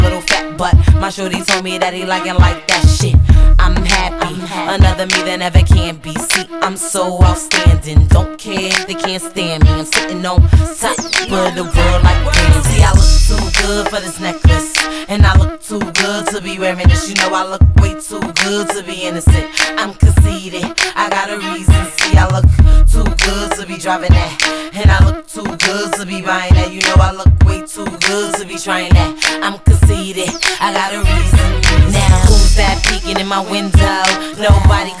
Жанр: Хип-Хоп / Рэп / R&B / Соул
R&B, Soul, Hip-Hop, Rap, East Coast Rap, Hardcore Rap